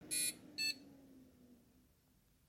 Komisches Geräusch, "schurrt" beim PC-Start Normal?!
Hallo zusammen :), ich habe mal ein Video angehangen, wo man hört das der PC nach dem einschalten so 1x "schnurrt" und dann 1 x kurz piept. 1.) 0:00...
Hallo zusammen :), ich habe mal ein Video angehangen, wo man hört das der PC nach dem einschalten so 1x "schnurrt" und dann 1 x kurz piept. 1.) 0:00 Ich klicke auf Schalter "klack" ab 0:04 kommt das "GRÖÖÖÖ" ab 0:05 kommt der normale einschalt-Ton das alles OK ist.